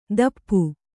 ♪ dappu